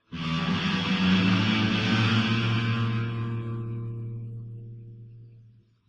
意大利面条西部吉他 " 西部危险刺杀04
标签： 沙漠 意大利面条 野生的 扭曲的 西方的 过载 吉他 环境 西部 牛仔
声道立体声